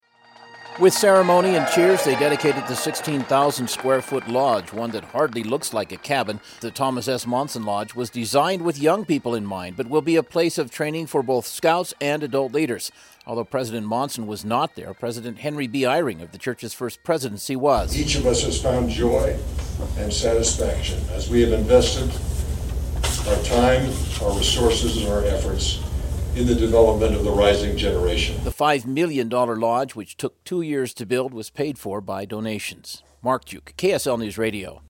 Salt Lake City